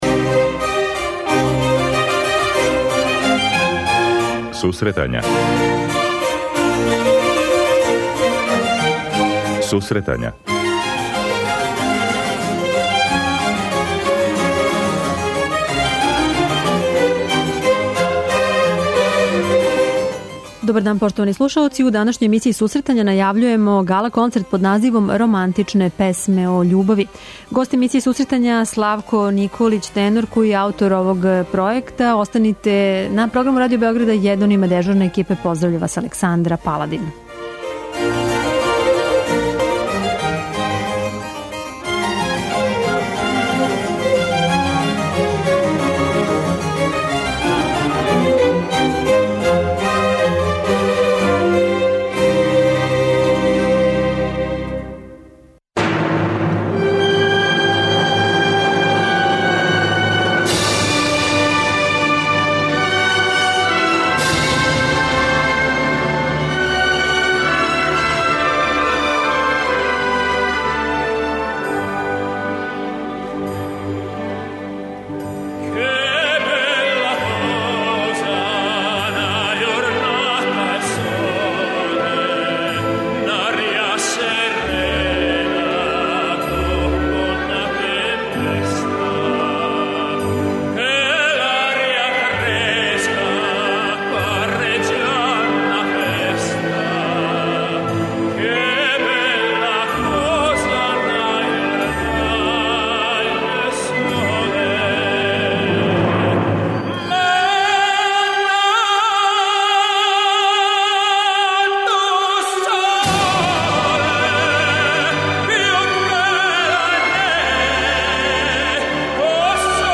преузми : 25.83 MB Сусретања Autor: Музичка редакција Емисија за оне који воле уметничку музику.